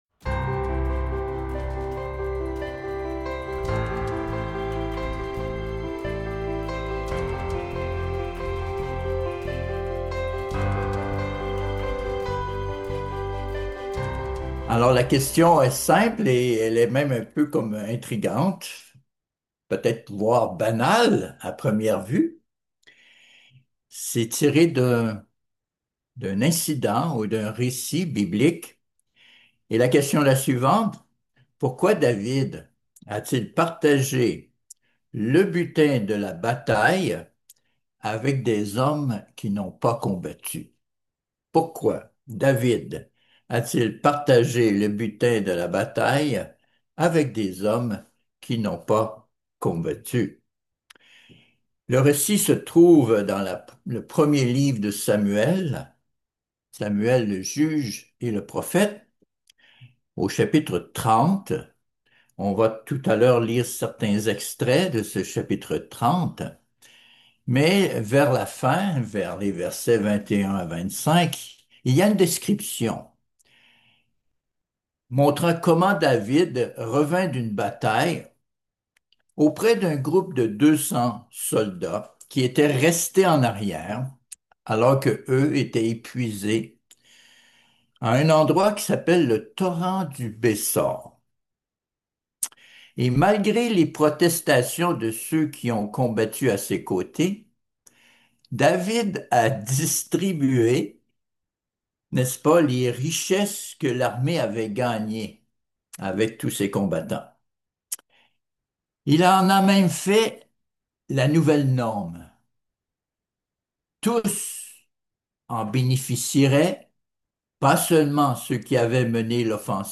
Given in Bordeaux